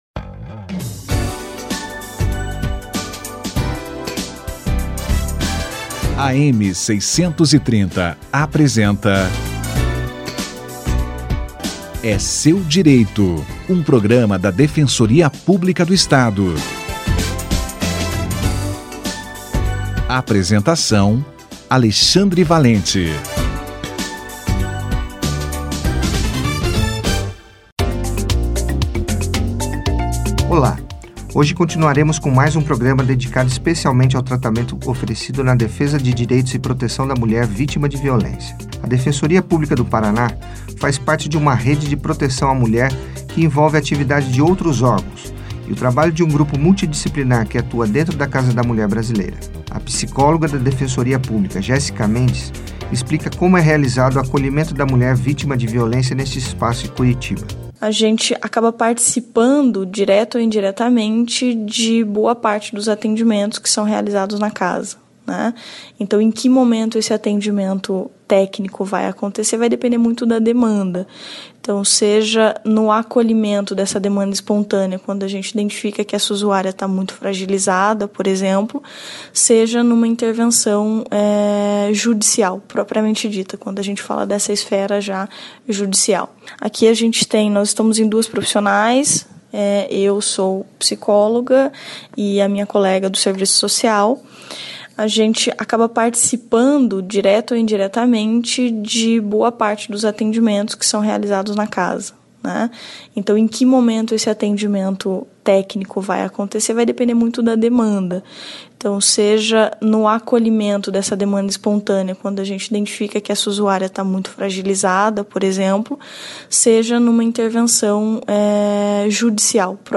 Atendimento psicológico na Casa da Mulher - Entrevista